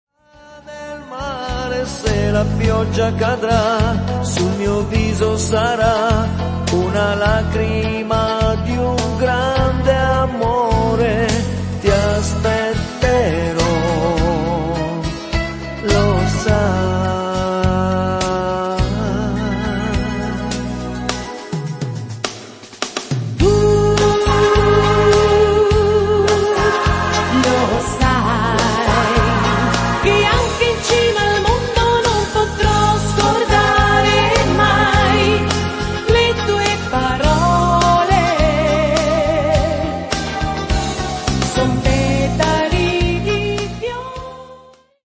lento